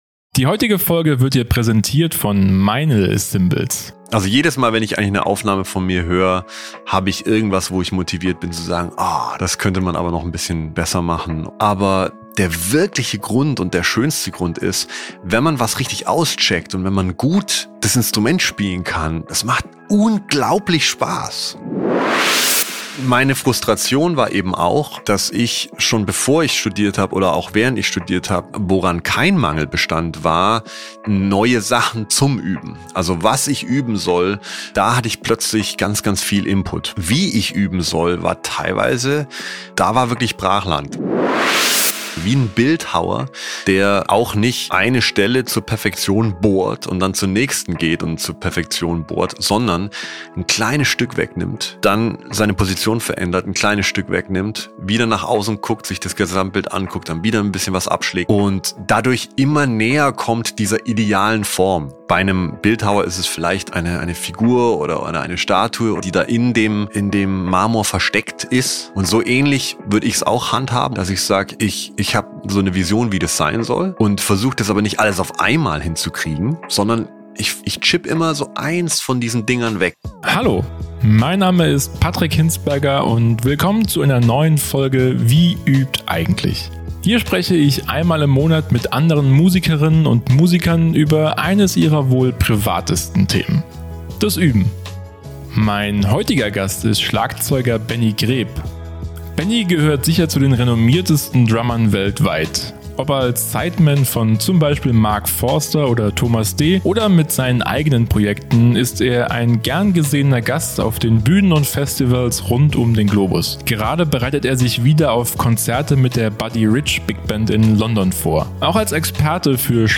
Genau darüber habe ich mit ihm gesprochen. Wir haben wichtige Voraussetzungen für gutes Üben diskutiert und Benny hat Tools und Methoden verraten, wie er an den Drums arbeitet.